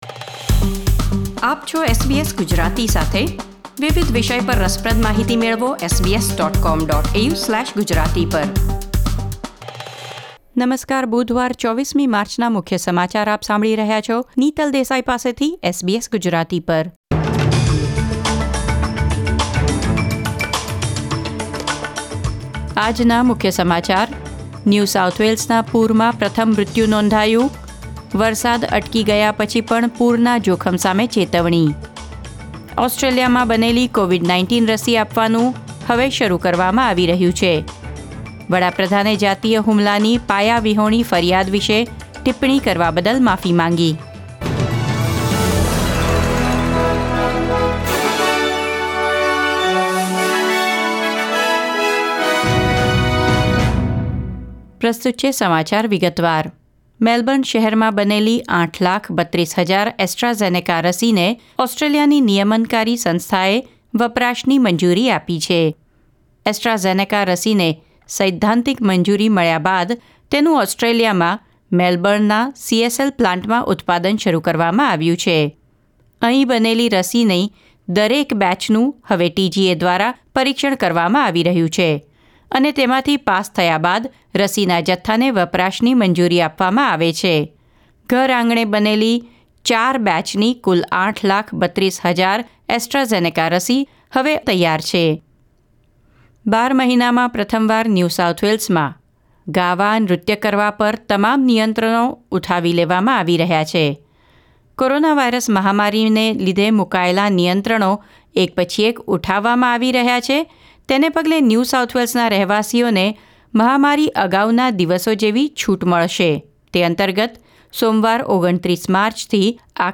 SBS Gujarati News Bulletin 24 March 2021